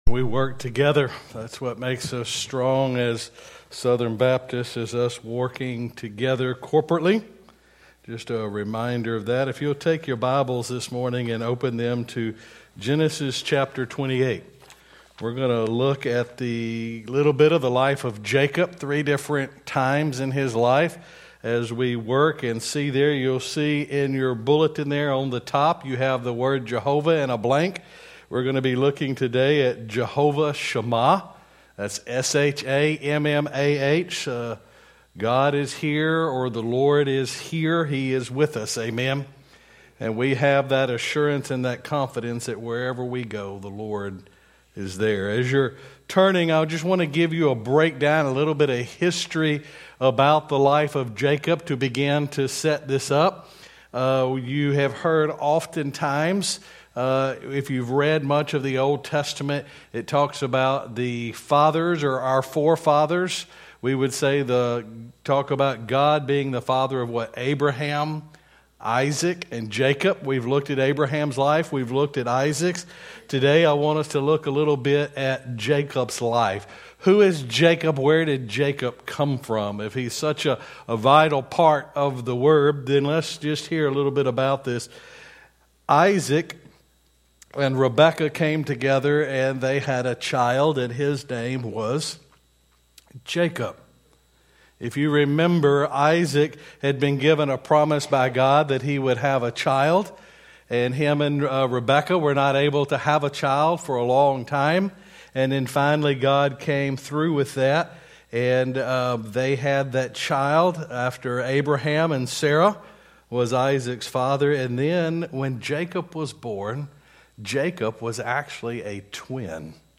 Sermons by Mt. Olivet Baptist Church Stanley, LA